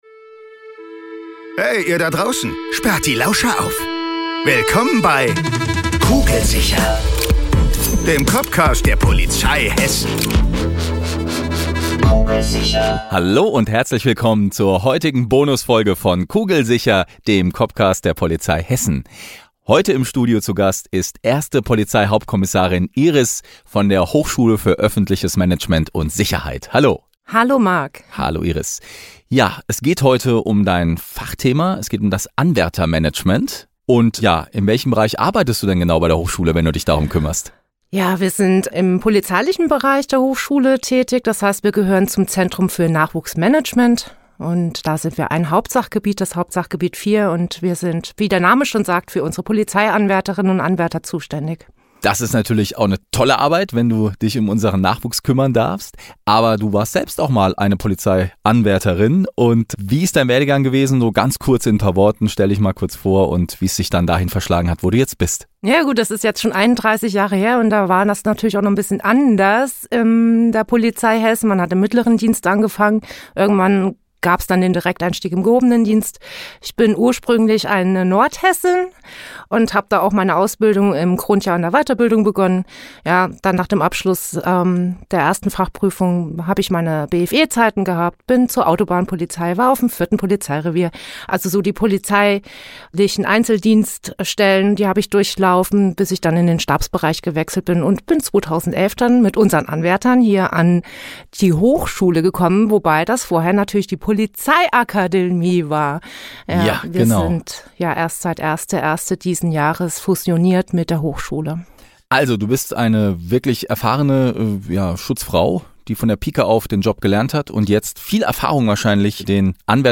Bei uns dreht sich alles um den echten Polizeialltag, mit echten Cops am Mikro.